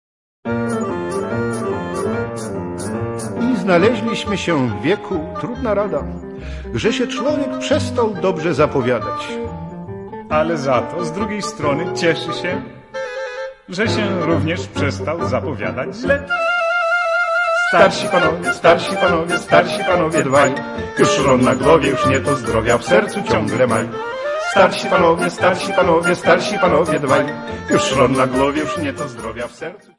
40 Hit Polish Cabaret Songs